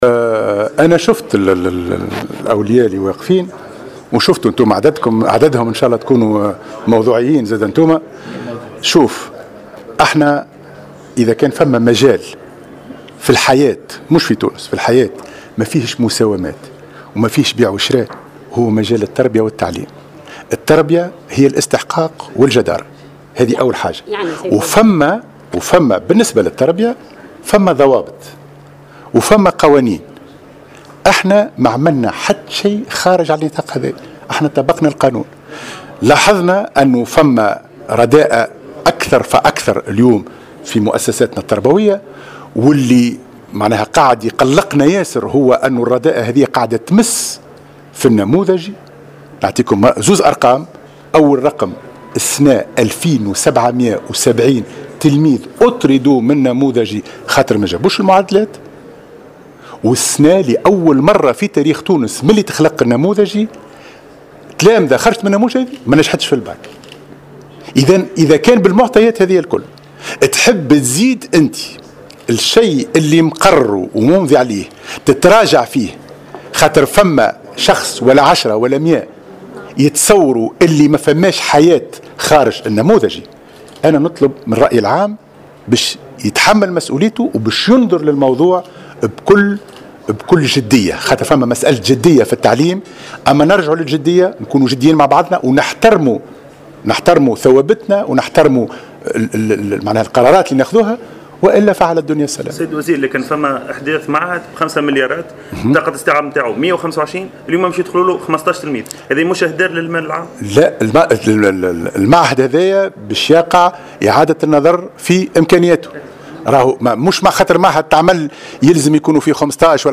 وقال في تصريح لمراسل "الجوهرة اف أم"، على هامش إشرافه في صفاقس على الاحتفال بيوم العلم الجهوي، إنه تم ضبط استراتيجية شاملة لتكوين المربين في اطار التكوين المستمر.